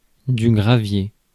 Ääntäminen
Synonyymit pierre calcul gravillon mignonnette jard garnotte Ääntäminen France: IPA: /ɡʁa.vje/ Haettu sana löytyi näillä lähdekielillä: ranska Käännös Substantiivit 1. slack 2. gravel 3. grit Suku: m .